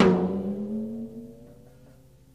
• Tom Sound F# Key 12.wav
Royality free tom one shot tuned to the F# note. Loudest frequency: 496Hz
tom-sound-f-sharp-key-12-8po.wav